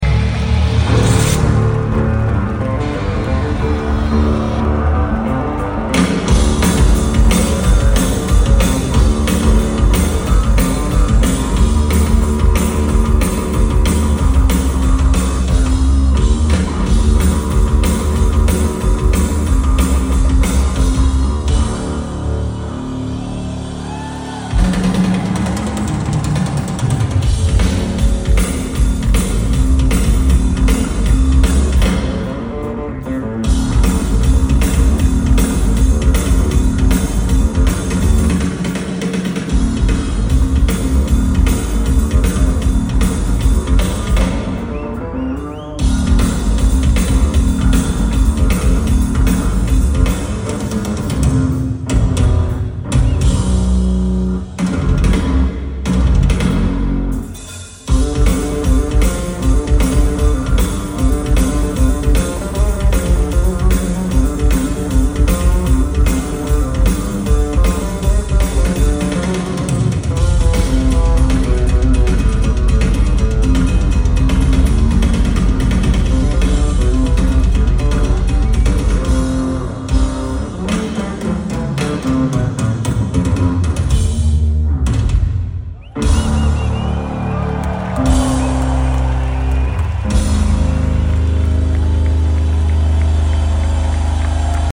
au Festival de Nîmes